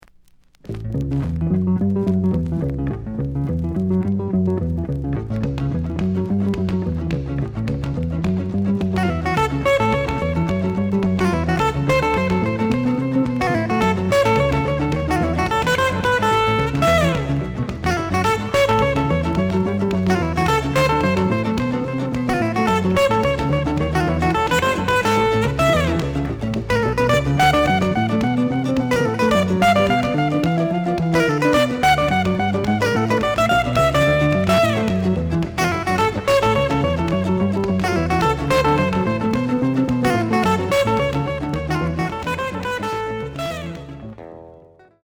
The audio sample is recorded from the actual item.
●Format: 7 inch
●Genre: Rock / Pop
Slight noise on A side.